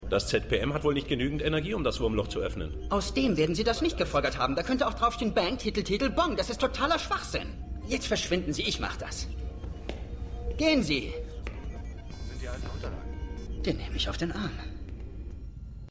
Datei) 249 KB {{Information |Beschreibung = Dr. McKay unterhält sich mit einem Techniker |Quelle = SGA 1x09 |Urheber = MGM |Datum = 31.08.2010 |Genehmigung = MGM |Andere Versionen = keine |Anmerkungen = }} 1
Dialog_1_SGA_1x09.mp3